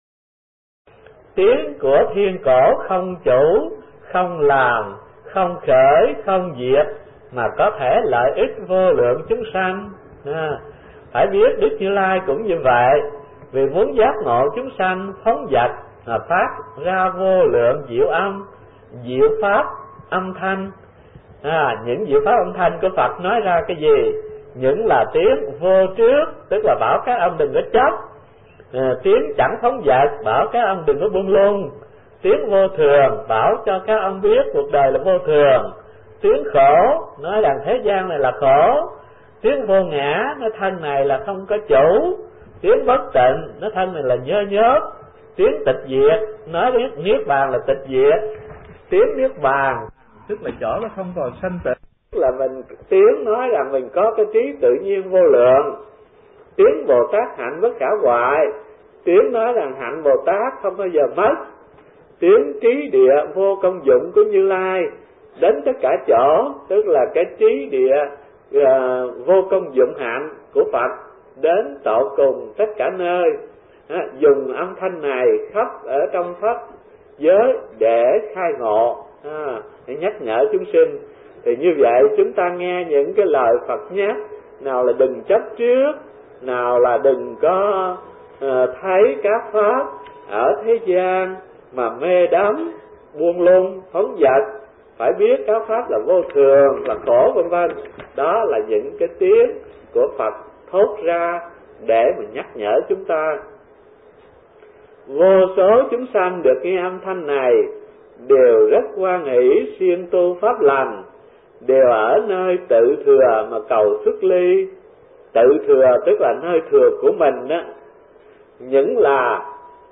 Kinh Giảng Kinh Hoa Nghiêm (Thiền Viện Linh Chiếu